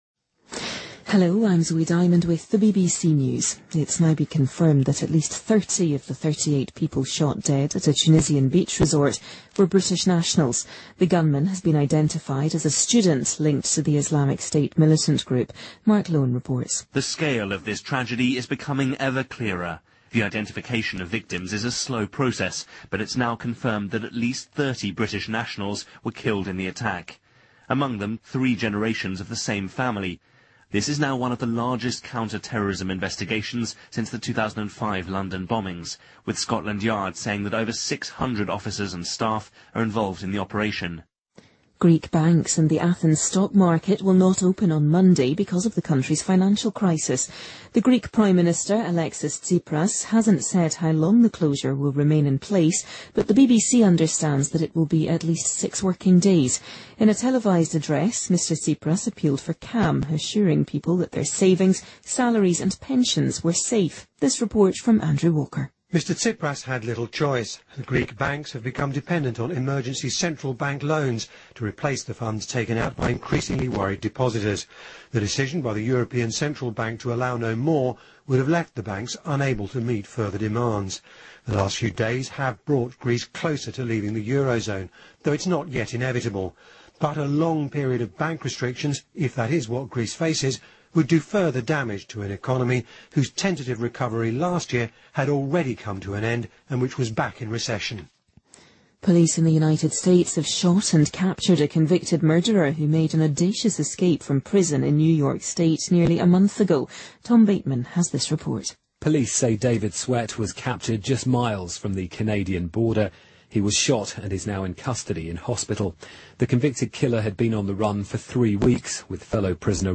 日期:2015-06-30来源:BBC新闻听力 编辑:给力英语BBC频道